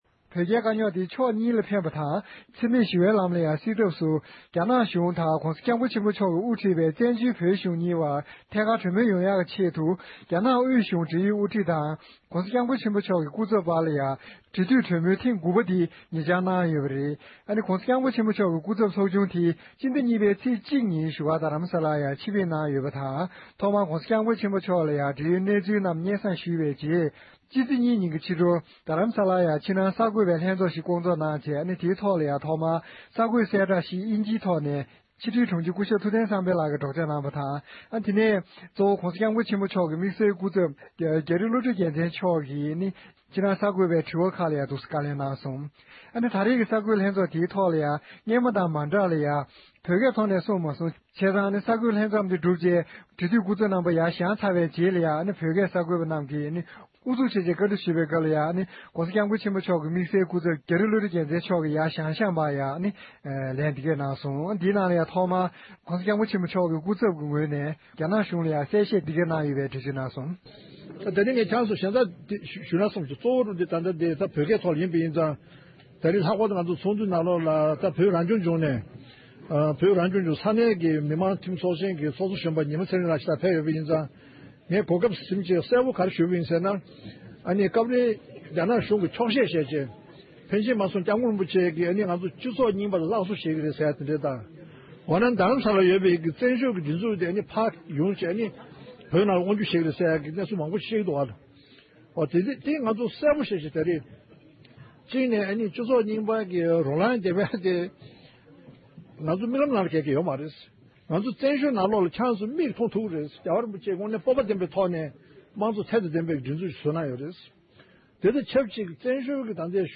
བོད་རྒྱ་འབྲེལ་མོལ་དགུ་པའི་གསར་འགོད་ལྷན་ཚོགས།
སྒྲ་ལྡན་གསར་འགྱུར།